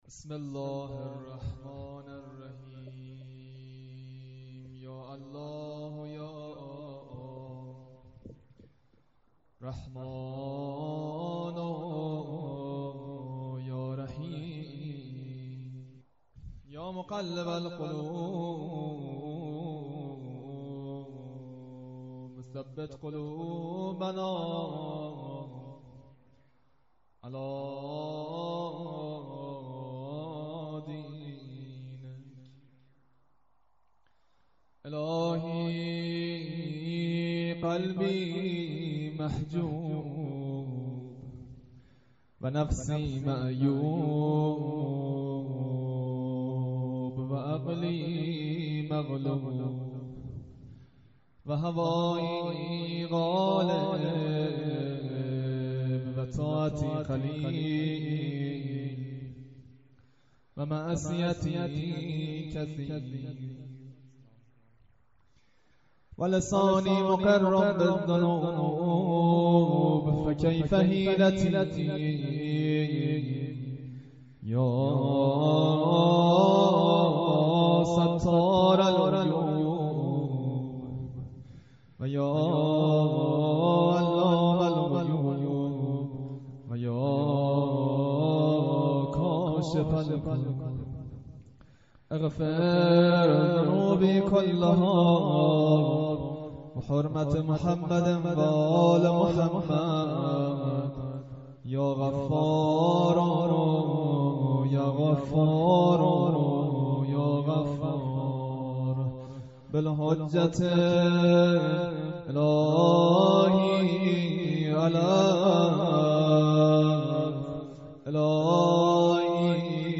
جلسه زیارت عاشورای هفتگی هیئت شهدای گمنام93/07/21 متاسفانه مرورگر شما، قابیلت پخش فایل های صوتی تصویری را در قالب HTML5 دارا نمی باشد.
مداحی